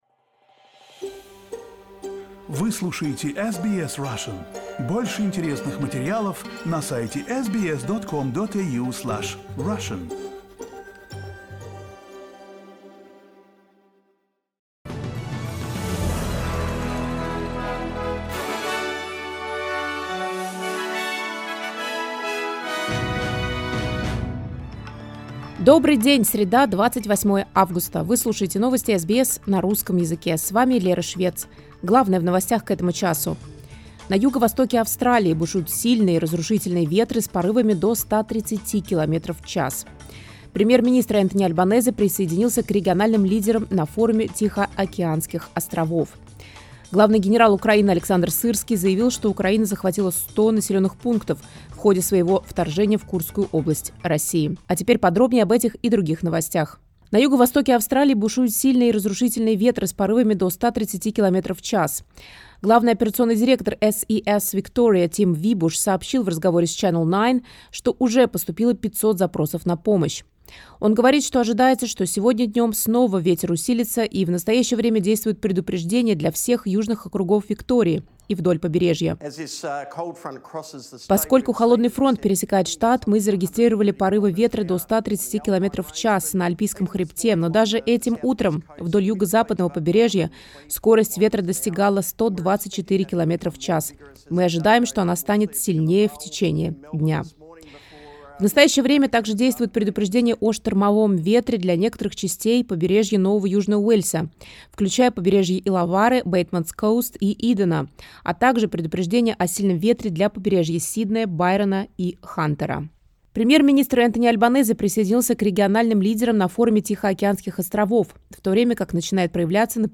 Новости SBS на русском языке — 28.08.2024